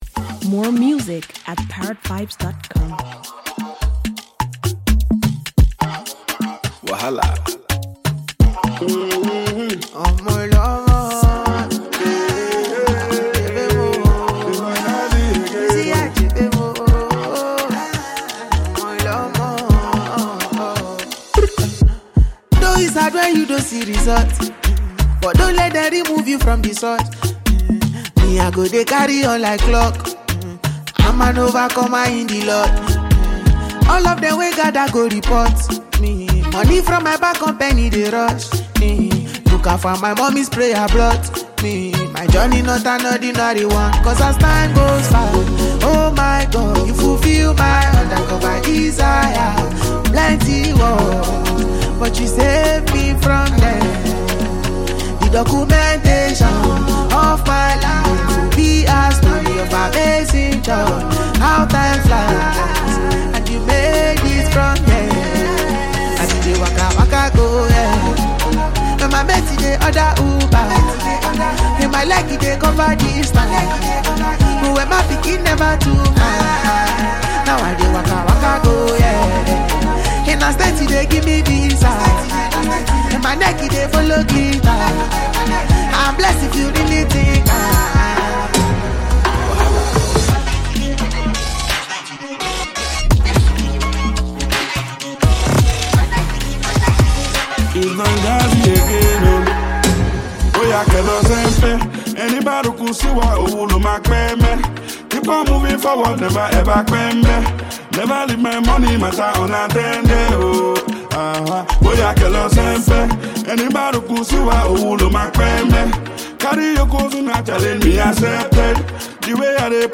mesmeric song